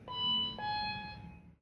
Lift arrival beep.mp3